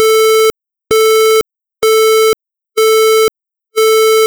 Il s'agit d'un son formé à partir d'une onde carrée de 440Hz. Les montées et les descentes sont, soit linéaires, soit exponentielles (linéaires en dB).